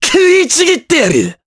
Mitra-Vox_Skill6_jp.wav